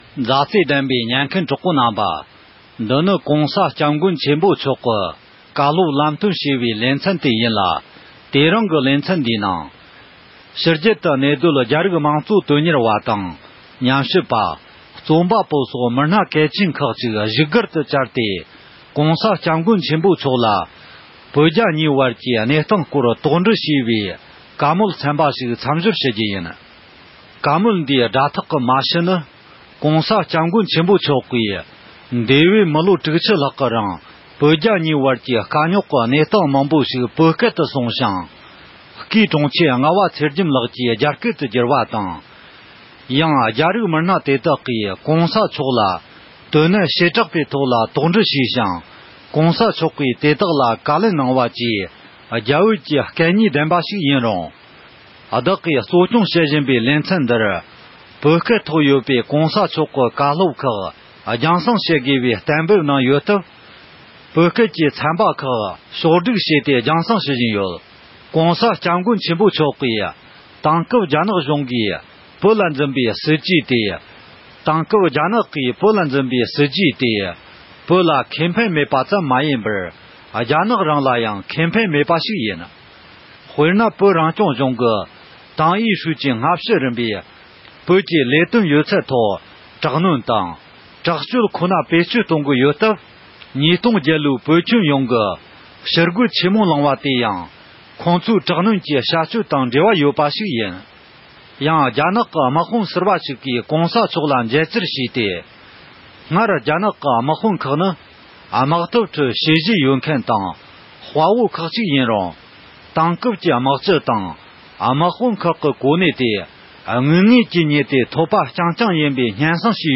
ཕྱི་རྒྱལ་དུ་གནས་སྡོད་རྒྱ་རིགས་མང་གཙོ་དོན་གཉེར་བ་རྣམས་དང་། ཉམས་ཞིབ་པ། དེ་བཞིན་རྩོམ་པ་པོ་སོགས་གལ་ཆེའི་མི་སྣ་ཁག་ཅིག་ལ་༸གོང་ས་མཆོག་ནས་བོད་རྒྱ་ཞི་མོལ་གྱི་སྐོར་ལ་བཀའ་སློབ་གནང་ཡོད་པ་རེད།